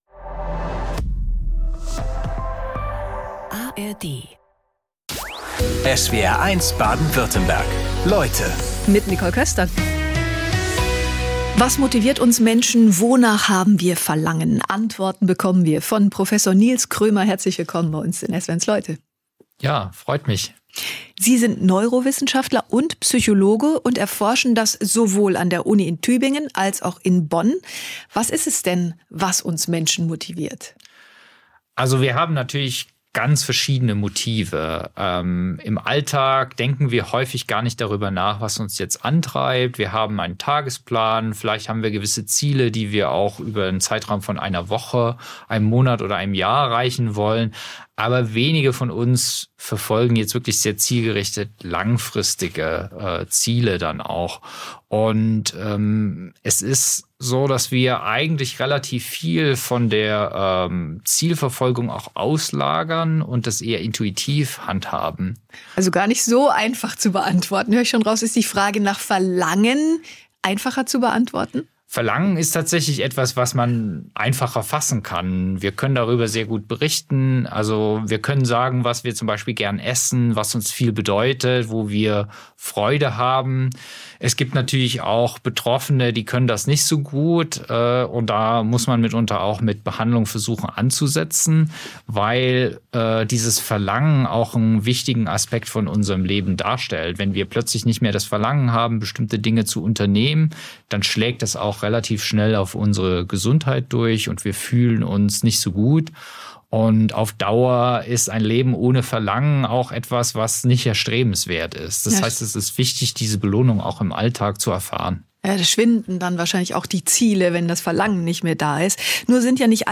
Talks mit besonderen Menschen und ihren fesselnden Lebensgeschichten aus Politik, Sport, Wirtschaft oder Wissenschaft.